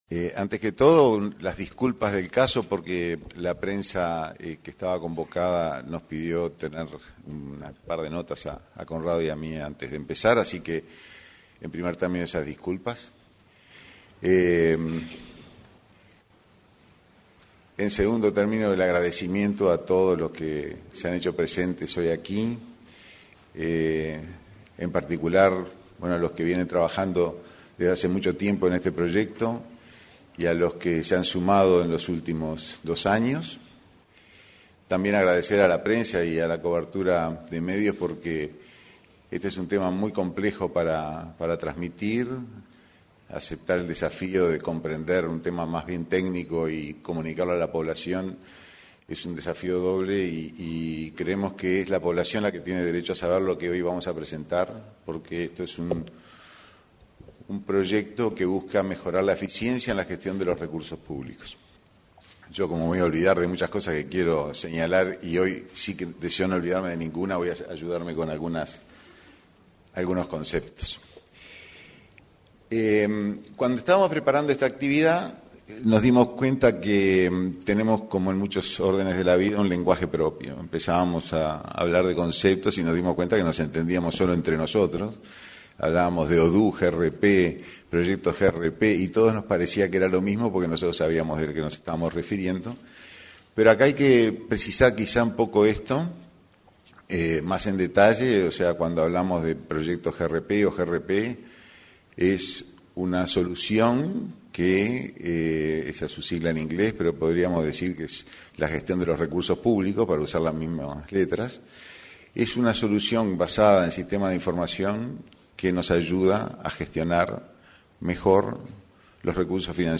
Este martes 24 de octubre, en el Auditorio de la Torre Ejecutiva anexa, se expresaron el director de la Agencia de Monitoreo y Evaluación de Políticas